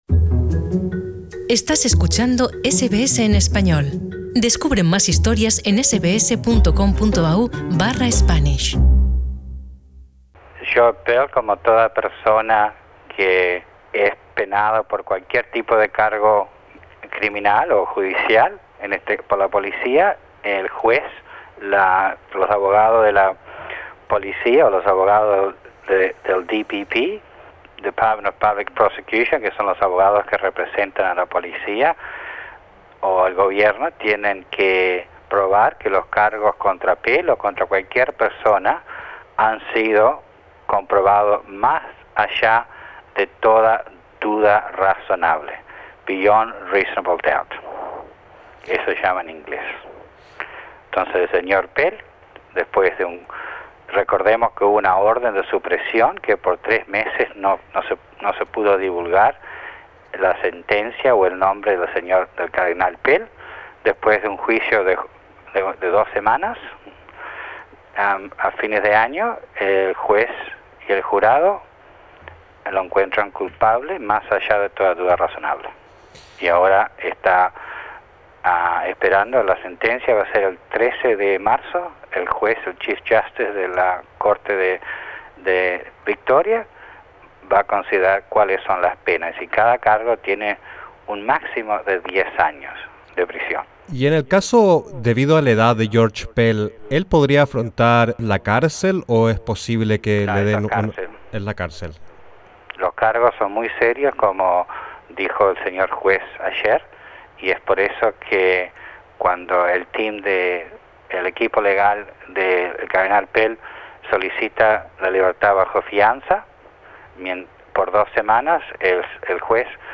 Un abogado de nuestra comunidad nos explica las claves, detalles e implicancias del mediático caso judicial de abuso sexual contra menores en contra el cardenal australiano.